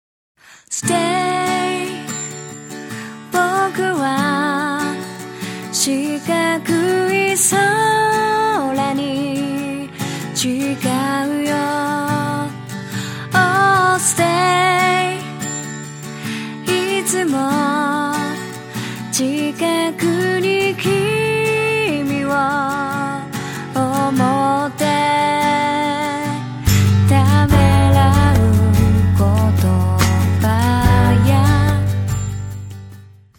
guitar ballad